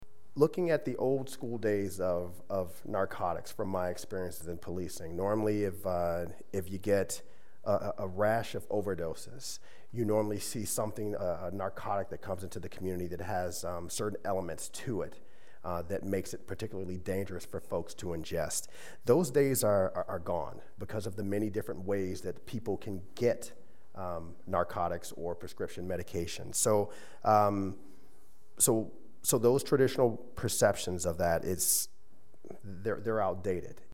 Director of RCPD Brian Peete spoke at Monday’s Law Board meeting.